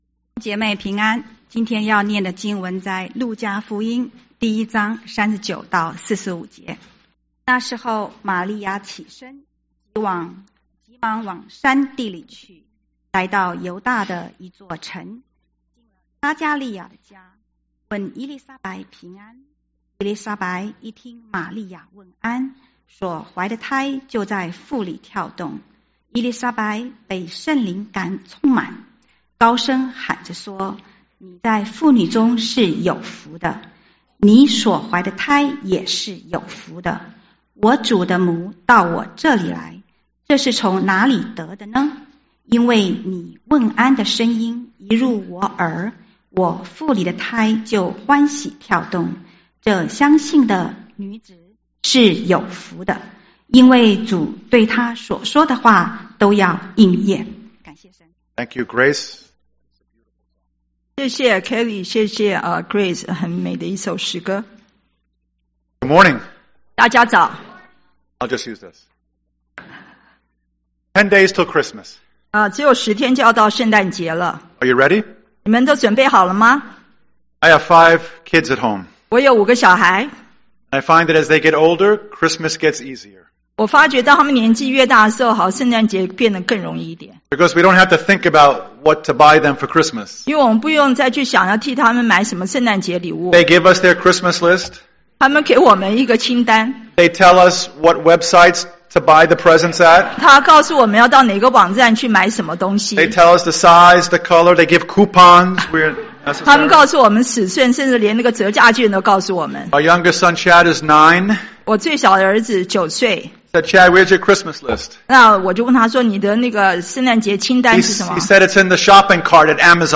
中文堂講道信息 | First Baptist Church of Flushing